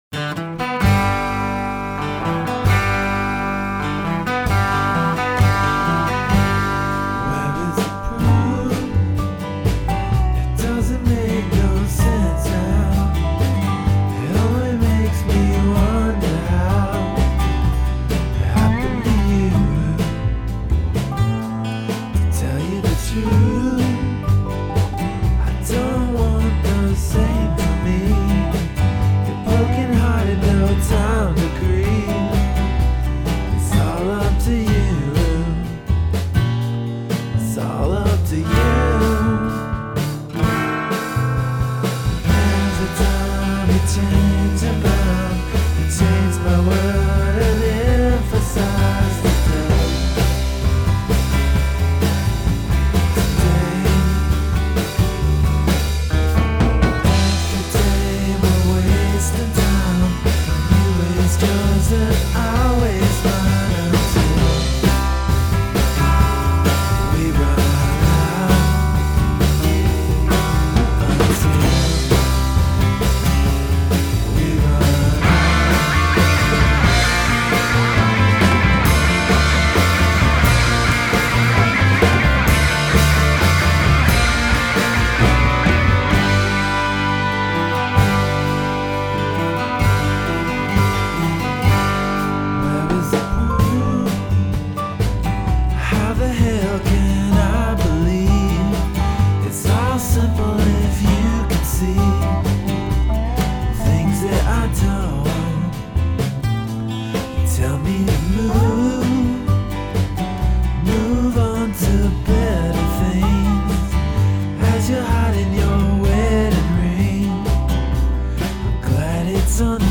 indie/power pop blend